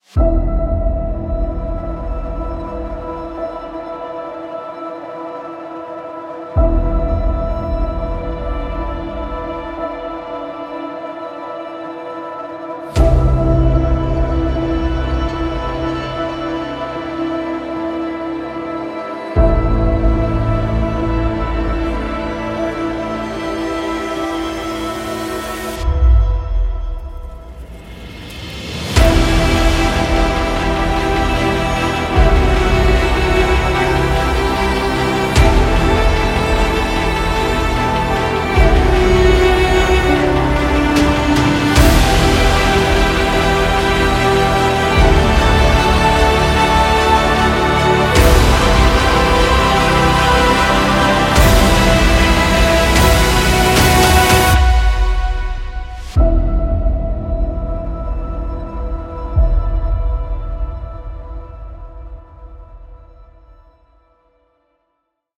Attention, le volume est automatiquement au maximum